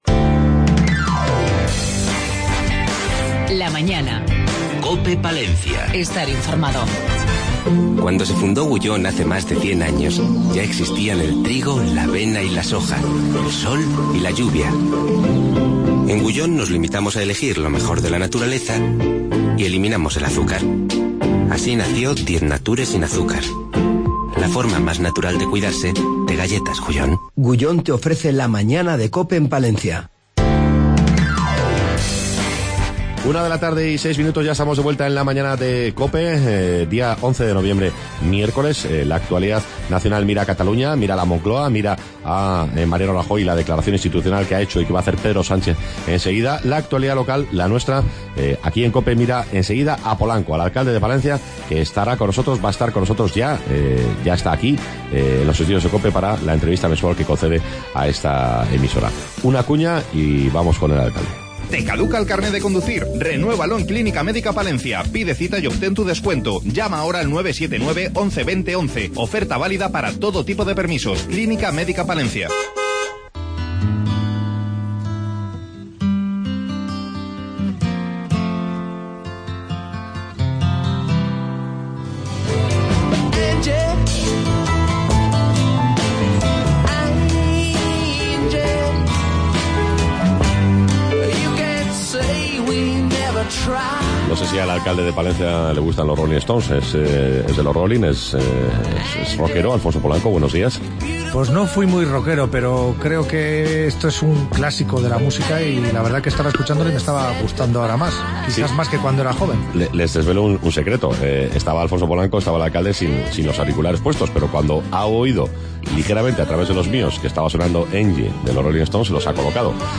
LA MAÑANA EN PALENCIA SEGUNDA HORA 11-11-15 Entrevista con Alfonso Polanco Alcalde de Palencia